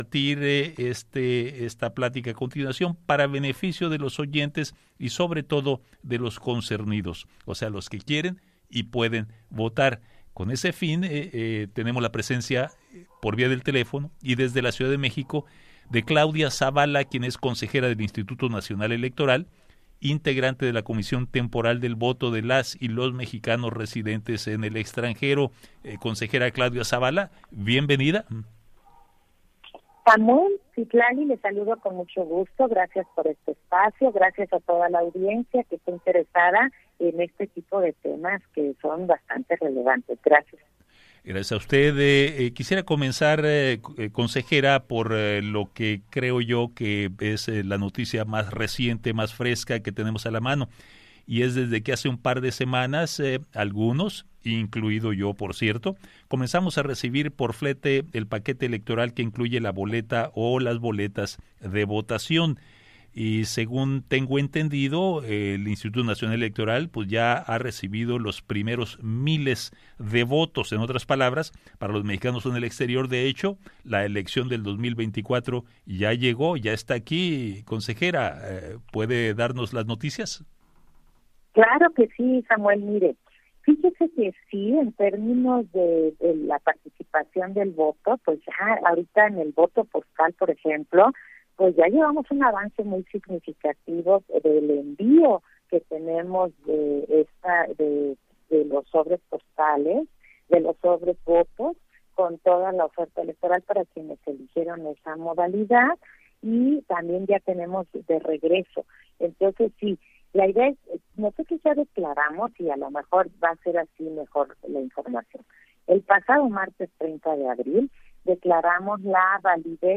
Escucha aquí el audio de la entrevista